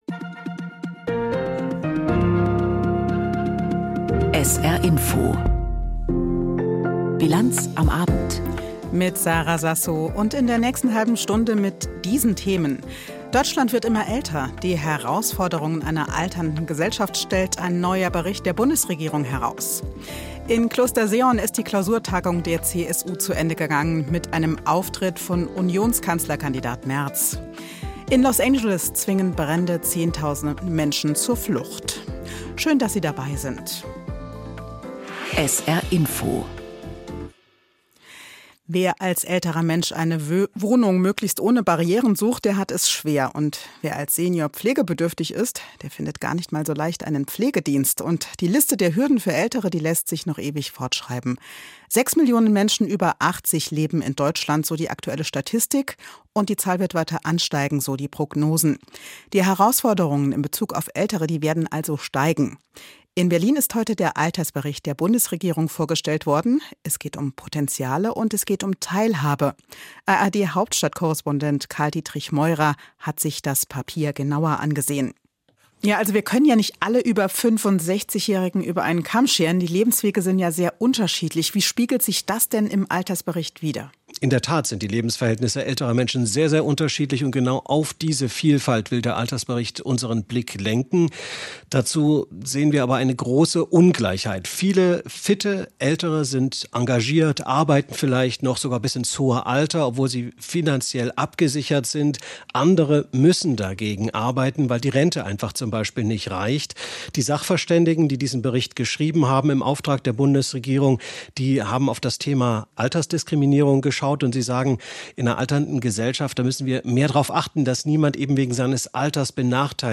Aktuelles und Hintergründe zu Entwicklungen und Themen des Tages aus Politik, Wirtschaft, Kultur und Gesellschaft in Berichten und Kommentaren.